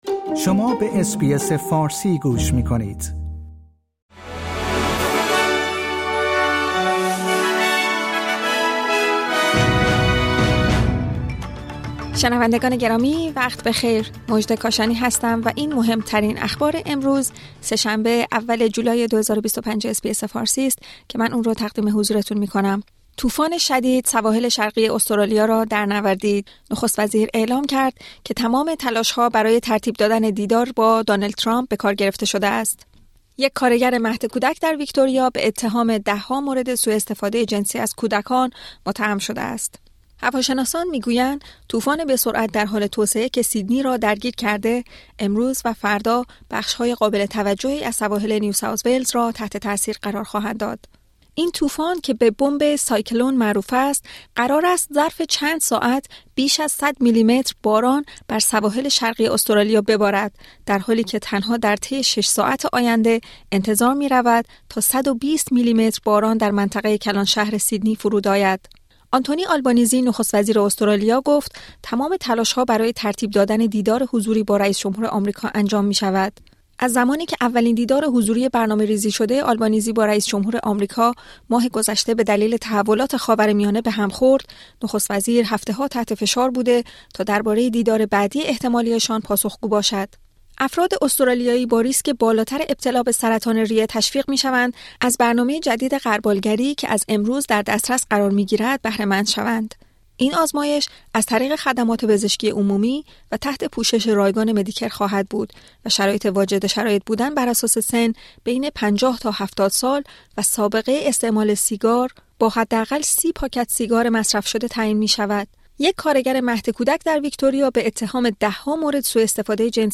در این پادکست خبری مهمترین اخبار امروز سه شنبه ۱ جولای ارائه شده است.